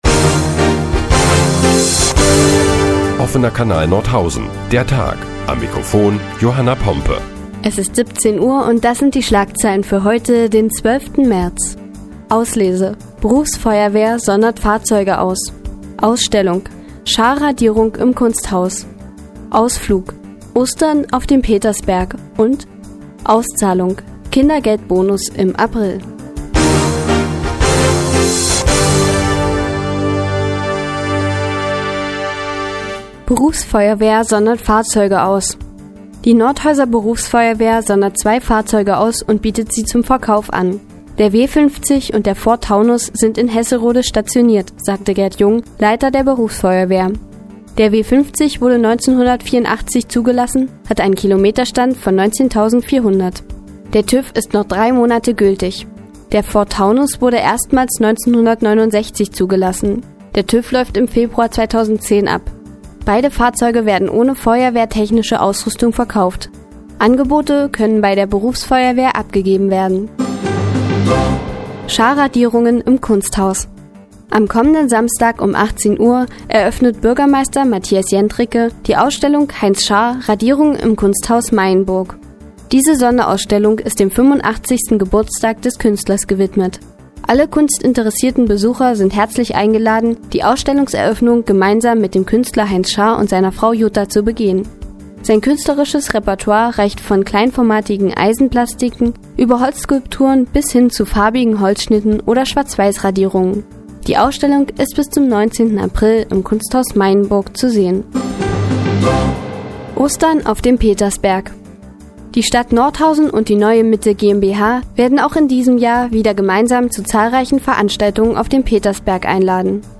Die tägliche Nachrichtensendung des OKN ist nun auch in der nnz zu hören. Heute geht es unter anderem um den Verkauf von ausgesonderten Feuerwehrfahrzeugen und Scharr-Radierungen im Kunsthaus.